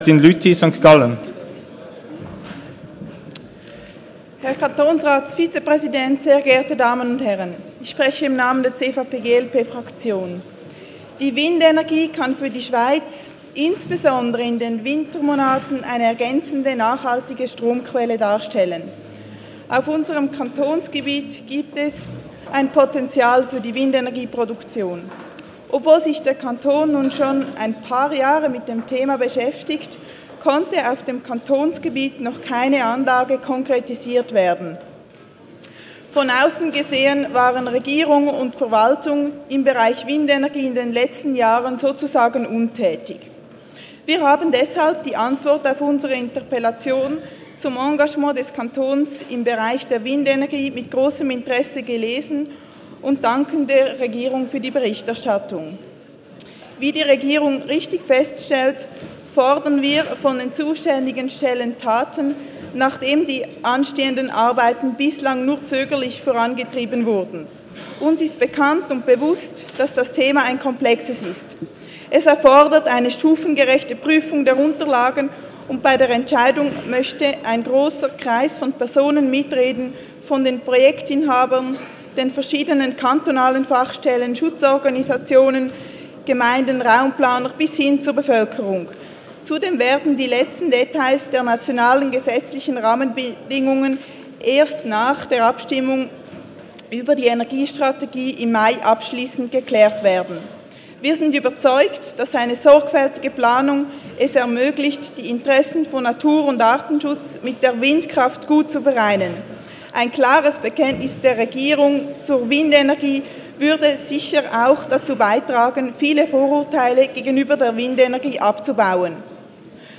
20.2.2017Wortmeldung
Session des Kantonsrates vom 20. und 21. Februar 2017